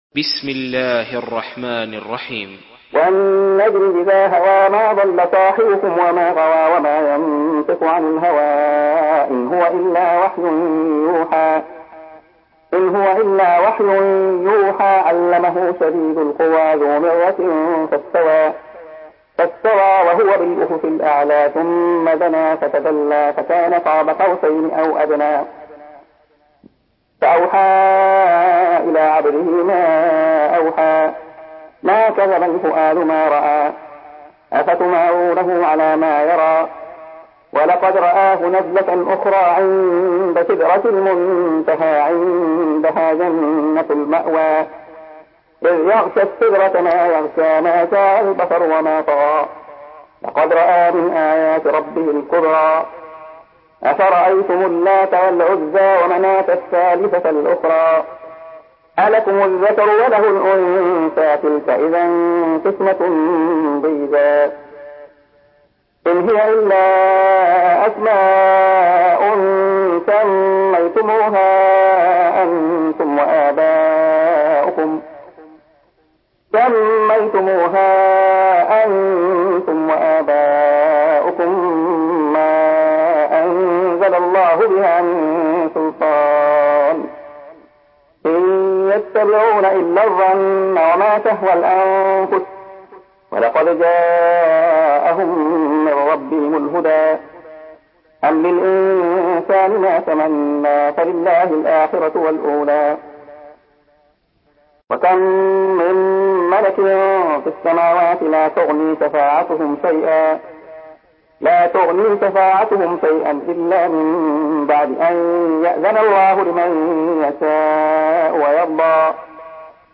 Surah An-Najm MP3 by Abdullah Khayyat in Hafs An Asim narration.
Murattal Hafs An Asim